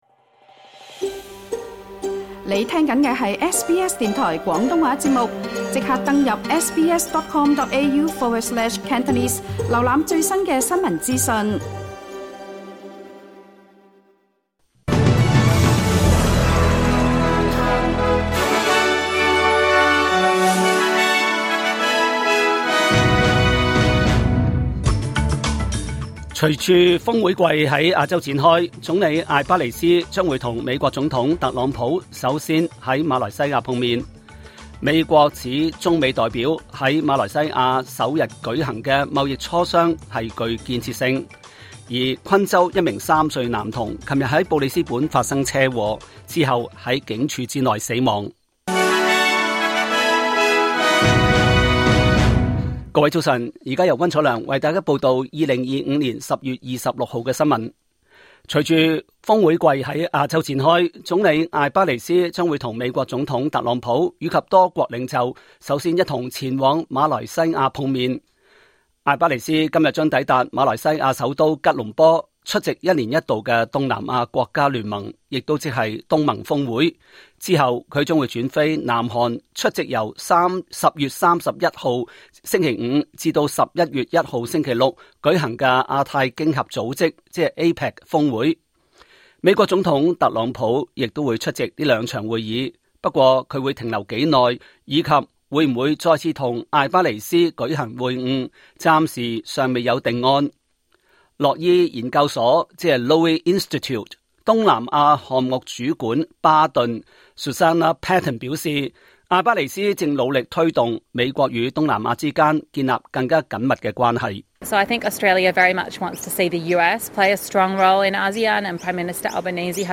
2025 年 10 月 26 日 SBS 廣東話節目詳盡早晨新聞報道。